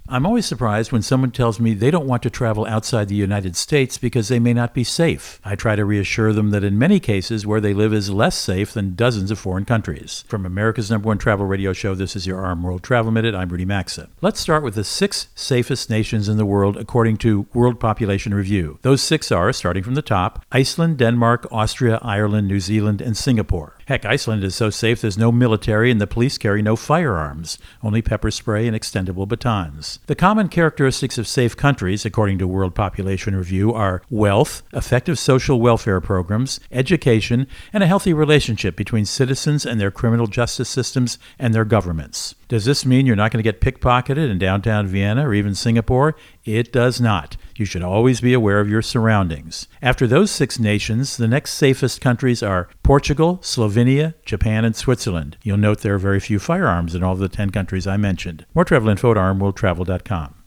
Co-Host Rudy Maxa | Thoughts on the World’s Safest Countries